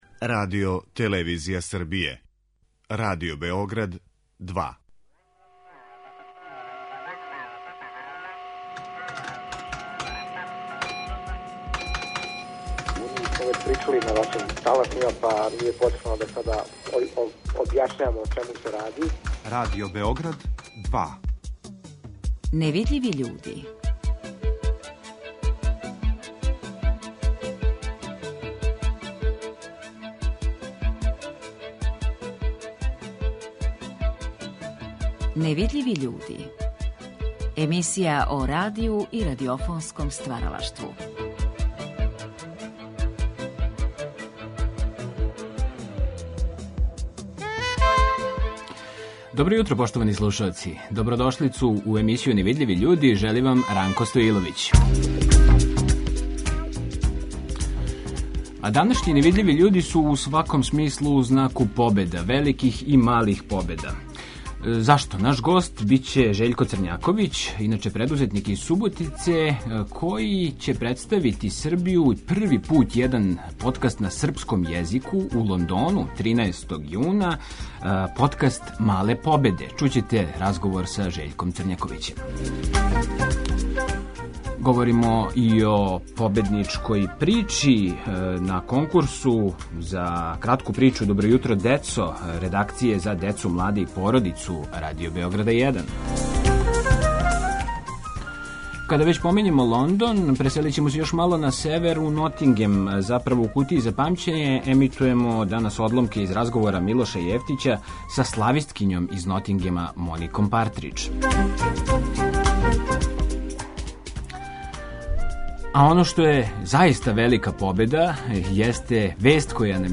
Ови разговори вођени су у Дубровнику 1985. године.